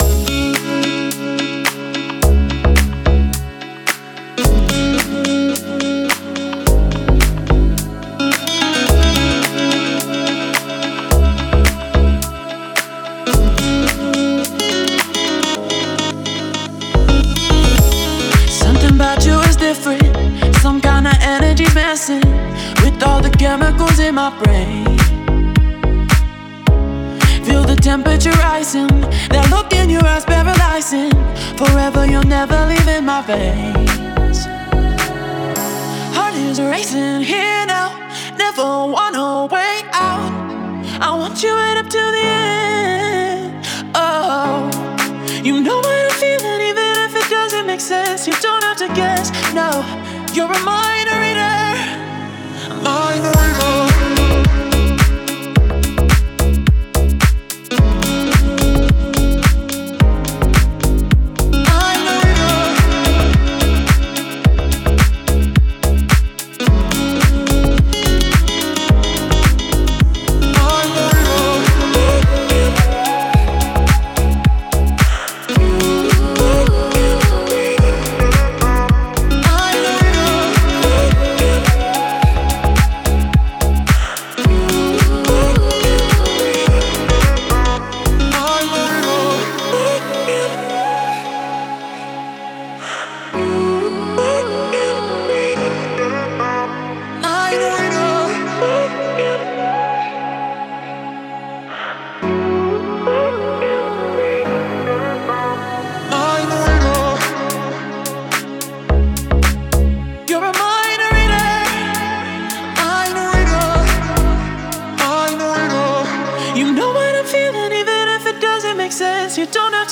это захватывающая поп-песня с элементами электронной музыки